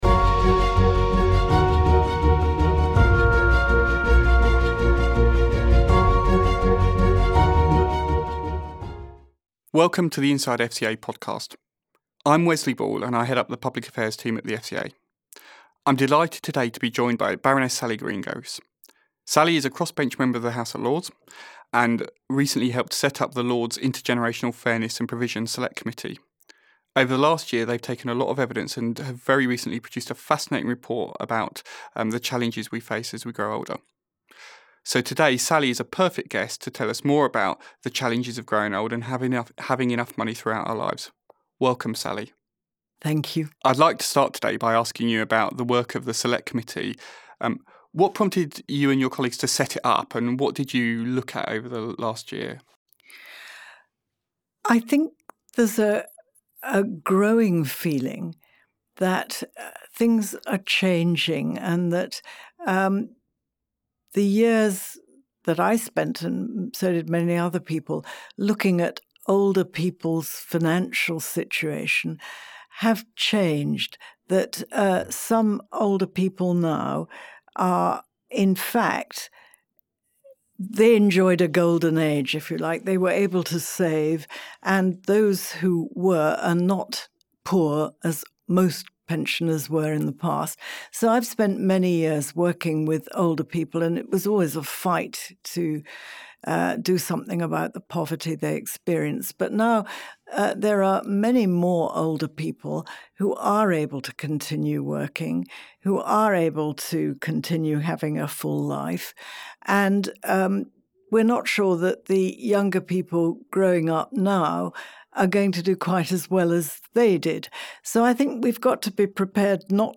Inside FCA Podcast: Interview with Sally Greengross on intergenerational issues